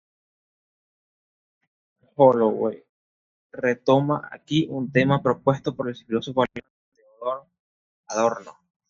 Pronounced as (IPA) /w/